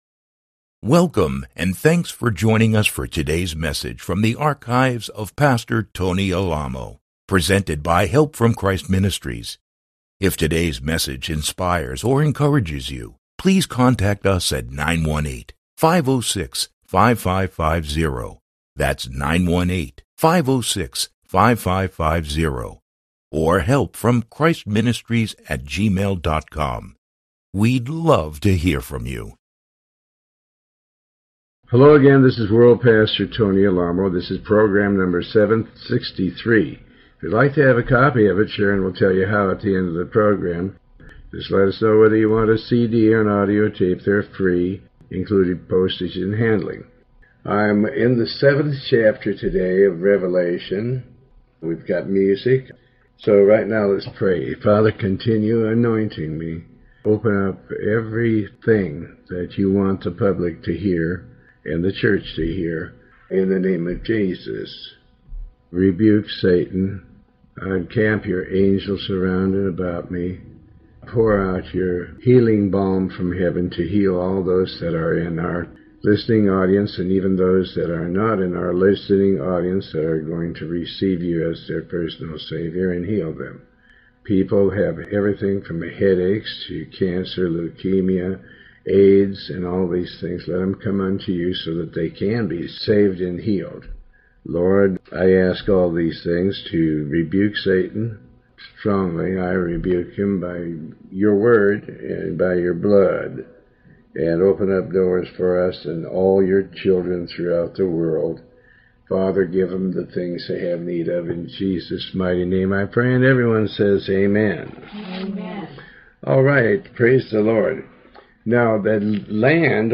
Pastor Alamo reads and comments on the Book of Revelation chapters 7-9. This program is part of a series covering the entire Book of Revelation